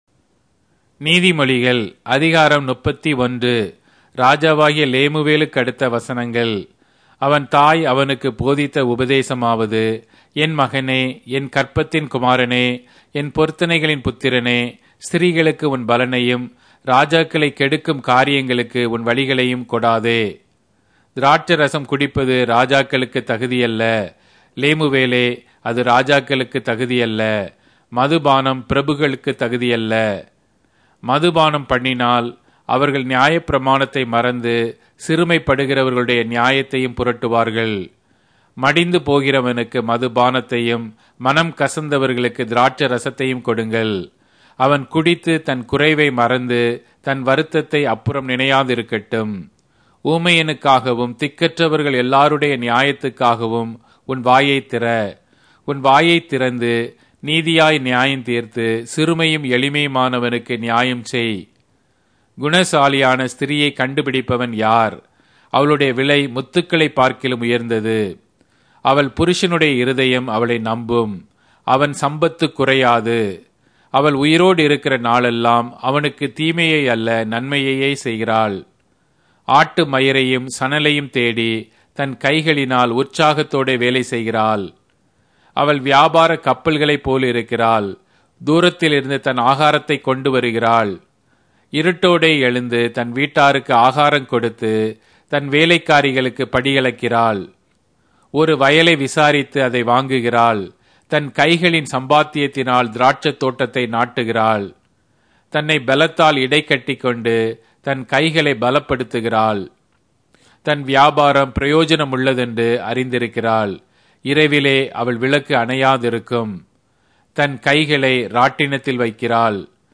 Tamil Audio Bible - Proverbs 27 in Irvor bible version